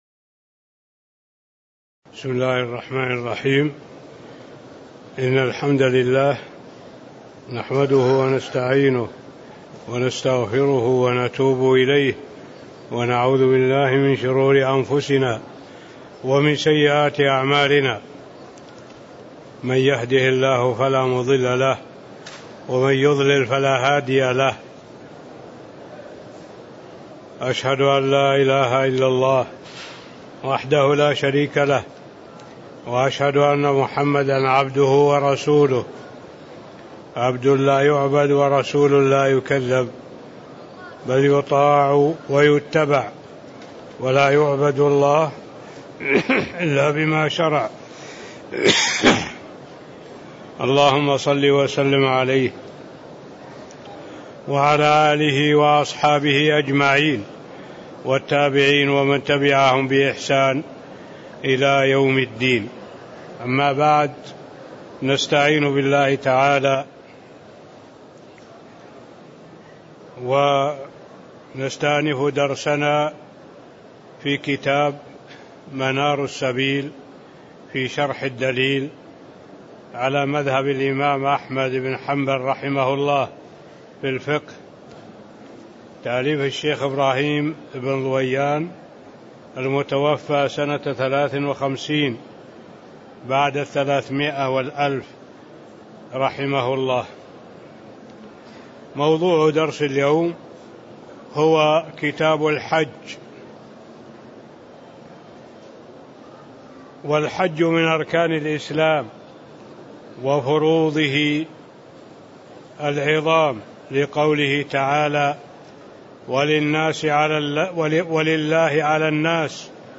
تاريخ النشر ٩ ذو القعدة ١٤٣٦ هـ المكان: المسجد النبوي الشيخ